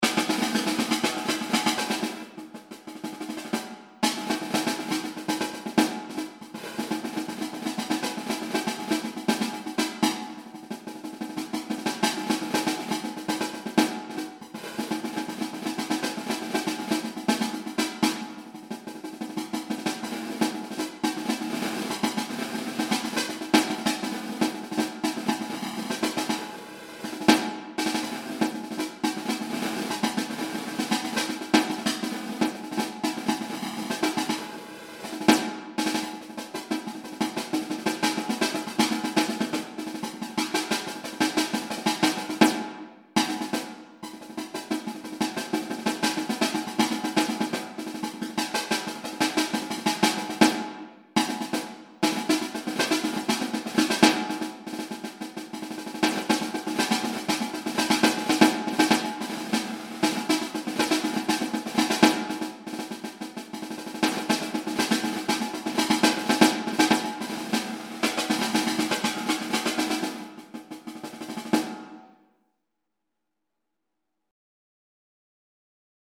Ongestemd Marcherend Slagwerk
Snare drum Cymbals Bass drum